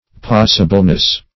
Passableness \Pass"a*ble*ness\, n. The quality of being passable.